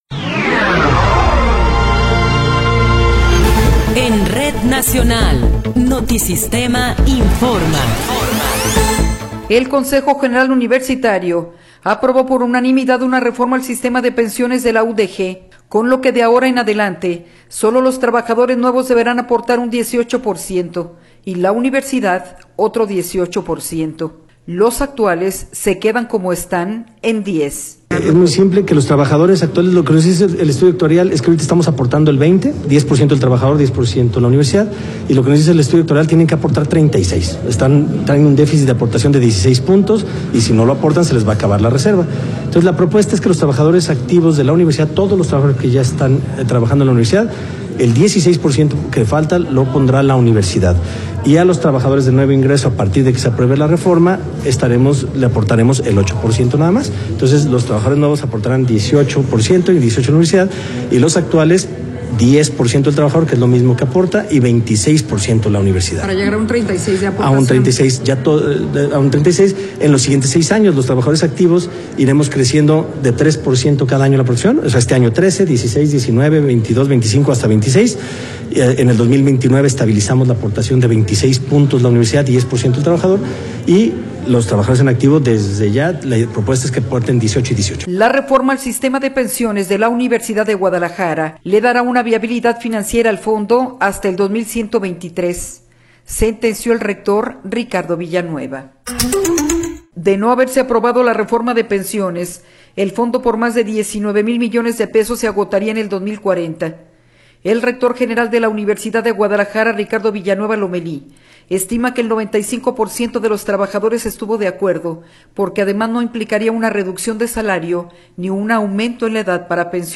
Noticiero 21 hrs. – 25 de Febrero de 2024
Resumen informativo Notisistema, la mejor y más completa información cada hora en la hora.